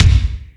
Lotsa Kicks(14).wav